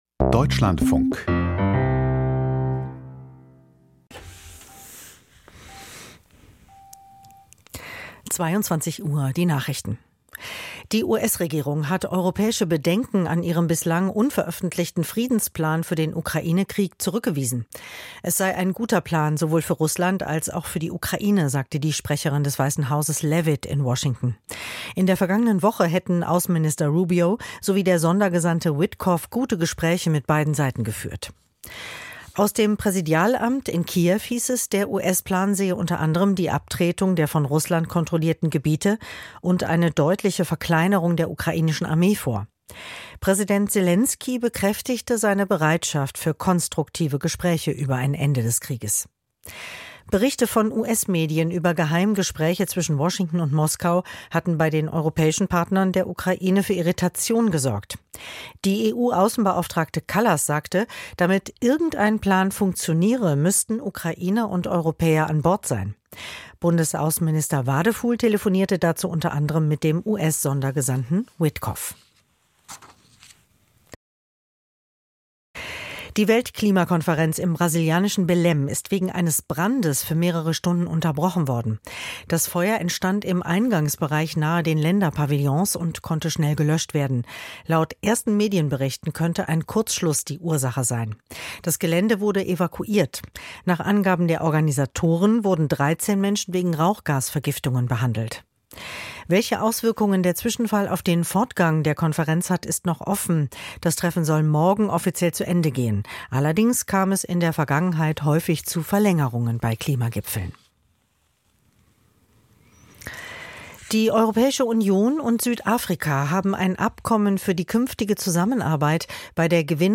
Die Nachrichten vom 20.11.2025, 21:59 Uhr
Die wichtigsten Nachrichten aus Deutschland und der Welt.